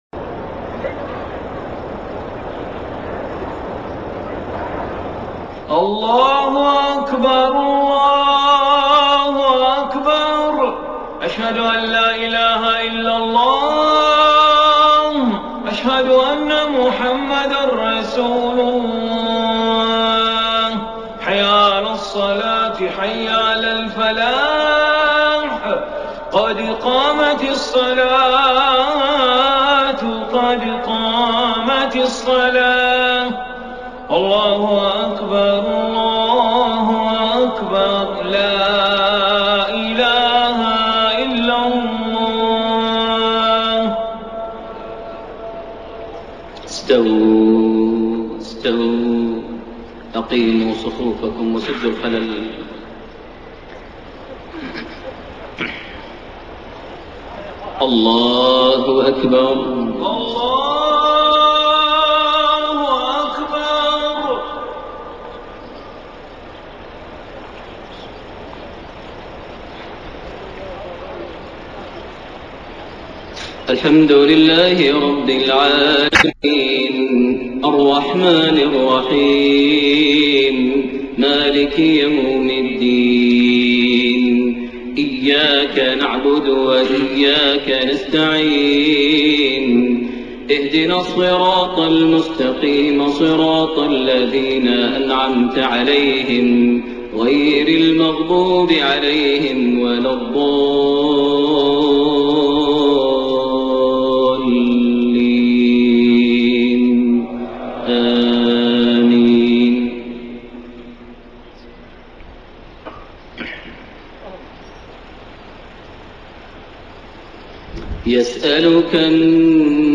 صلاة المغرب 20 رجب 1433هـ خواتيم سورة الاحزاب 63-73 > 1433 هـ > الفروض - تلاوات ماهر المعيقلي